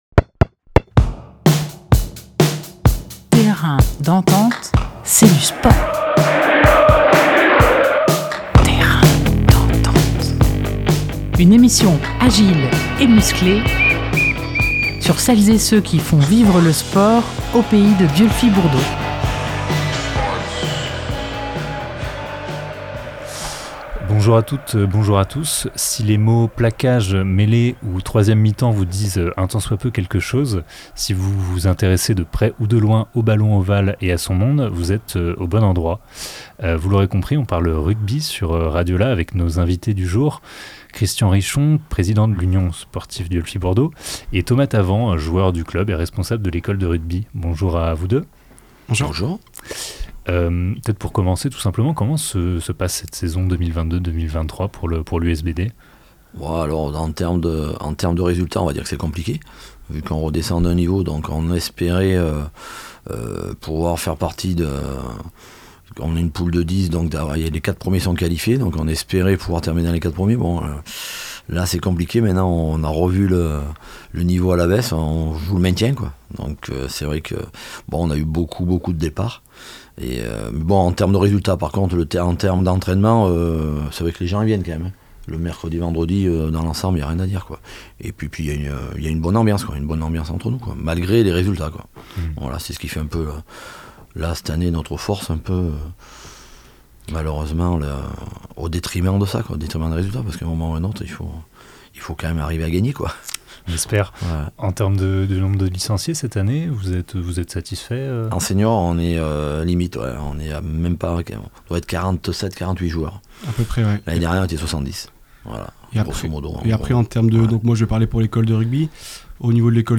26 janvier 2023 9:30 | actus locales, Interview, Terrain d'entente